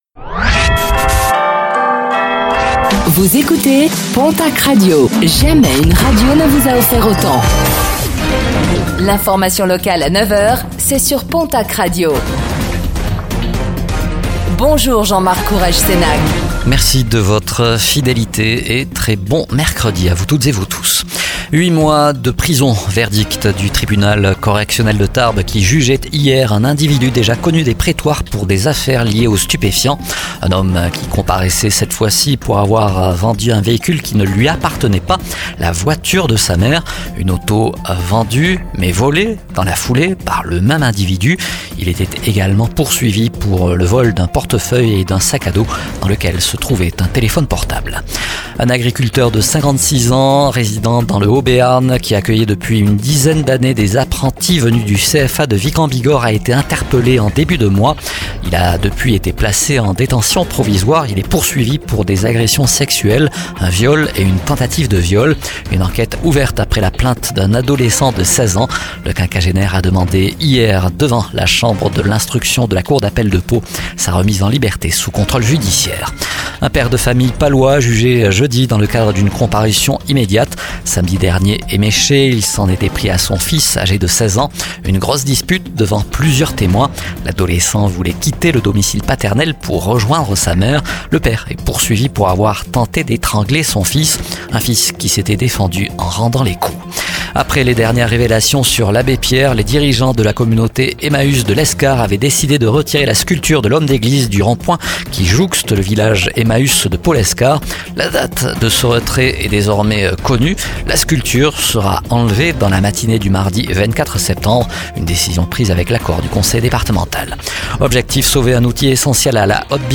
09:05 Écouter le podcast Télécharger le podcast Réécoutez le flash d'information locale de ce mercredi 18 septembre 2024